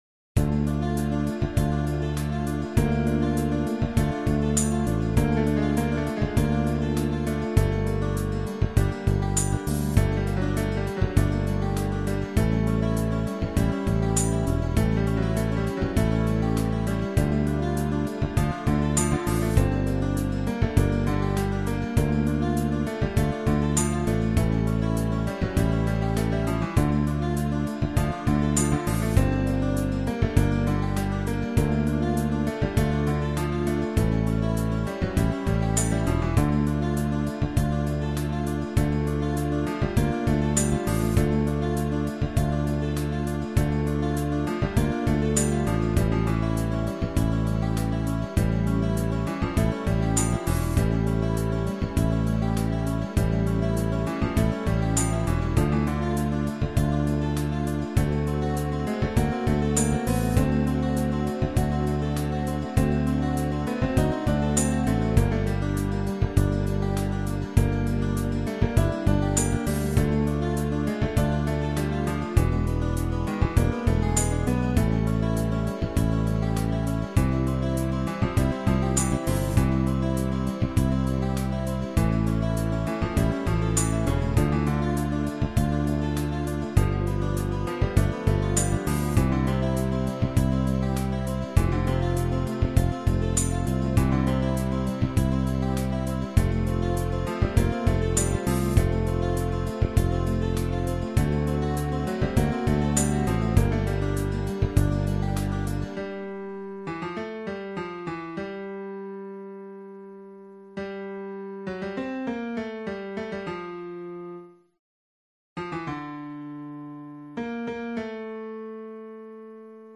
Рубрика: Поезія, Авторська пісня
гарна пісня!